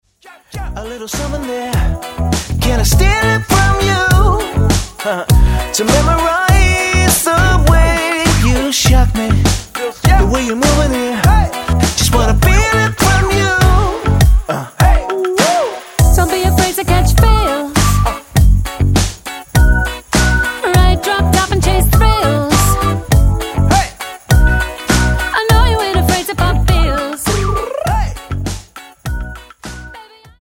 Tonart:Em Multifile (kein Sofortdownload.
Die besten Playbacks Instrumentals und Karaoke Versionen .